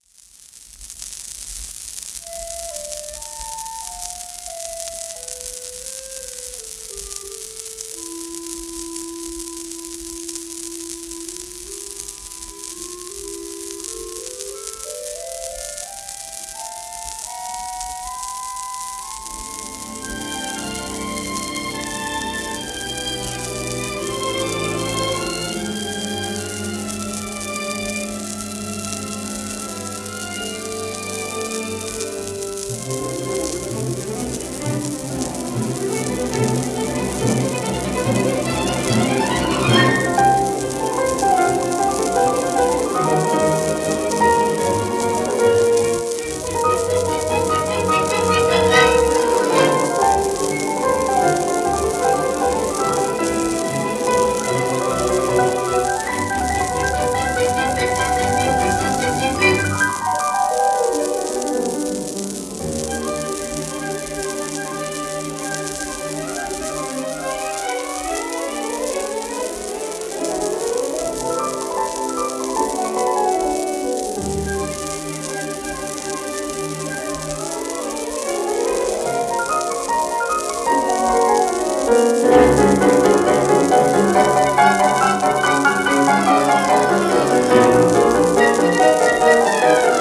1932年録音